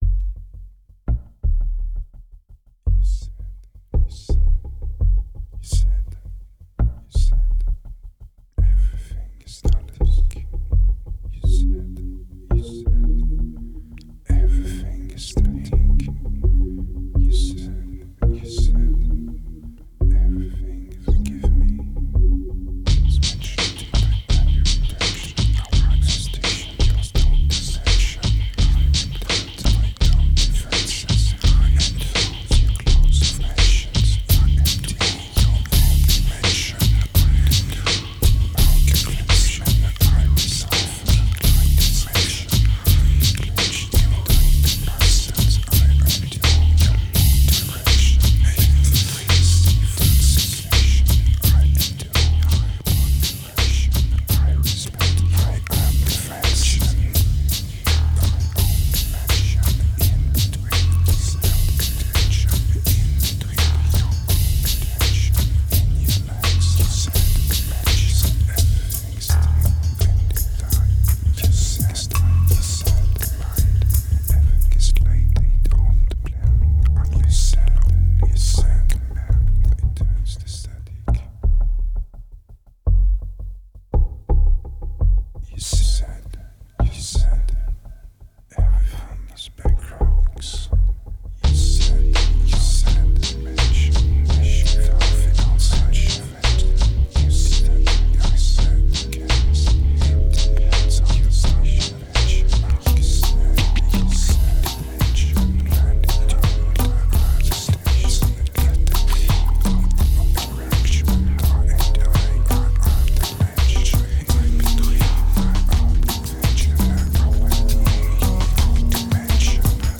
2386📈 - 2%🤔 - 84BPM🔊 - 2009-09-01📅 - -158🌟